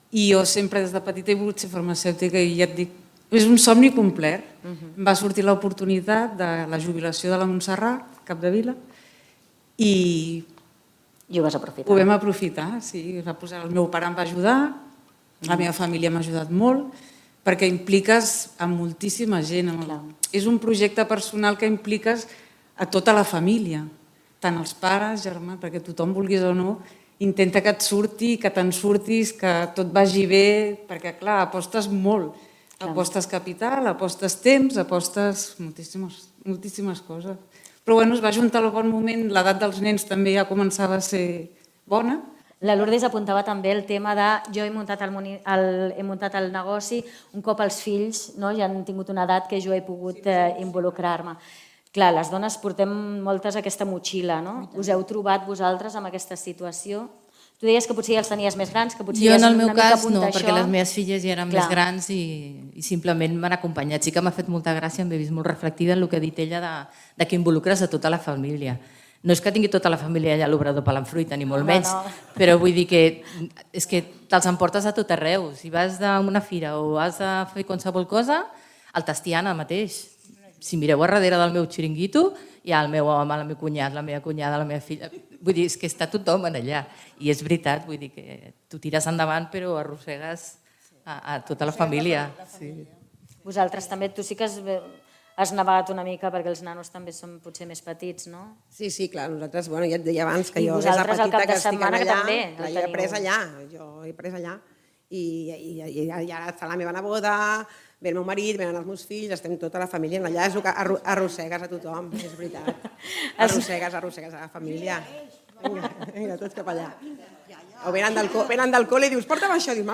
L’acte institucional del 8M, que s’ha celebrat aquest divendres 7 de març a Can Riera, ha donat el tret de sortida a les activitats programades a Tiana per aquest mes de març amb motiu del Dia de la Dona.
L’acte va finalitzar amb una taula rodona